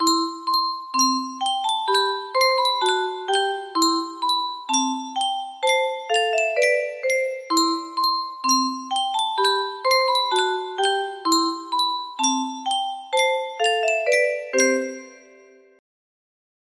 Clone of Yunsheng Spieluhr - Ein feste Burg ist unser Gott 3176 music box melody